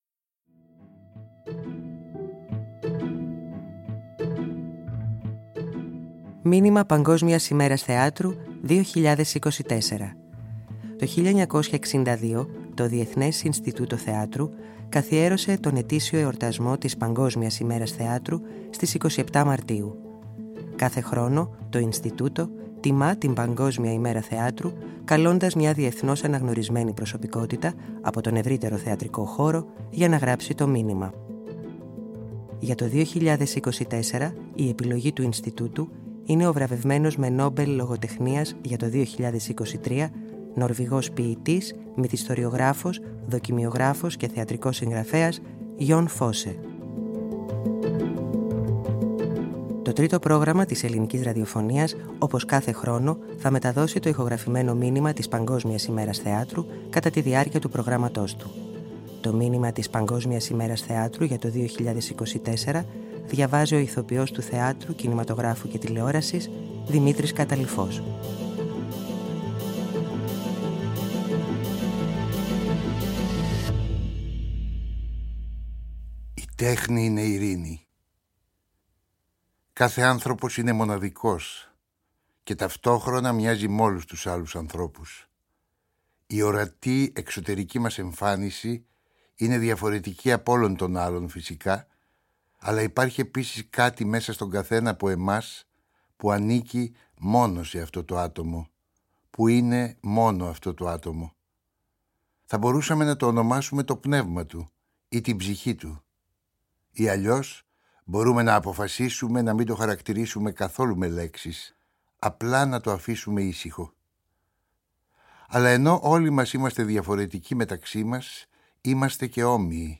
Ακούστε το μήνυμα του Διεθνούς Ινστιτούτου Θεάτρου που για φέτος έγραψε ο Νορβηγός ποιητής, μυθιστοριογράφος, δοκιμιογράφος και θεατρικός συγγραφέας Γιον Φόσσε, με τη φωνή του καταξιωμένου Έλληνα Ηθοποιού Δημήτρη Καταλειφού όπως μεταδόθηκε στη διάρκεια της ημέρας από το Τρίτο Πρόγραμμα.
Το Τρίτο Πρόγραμμα  της Ελληνικής Ραδιοφωνίας όπως κάθε χρόνο μετέδωσε το ηχογραφημένο Μήνυμα της Παγκόσμιας Ημέρας Θεάτρου κατά τη διάρκεια του προγράμματός του.
Το μήνυμα διαβάζει ο καταξιωμένος ‘Eλληνας ηθοποιός Δημήτρης Καταλειφός.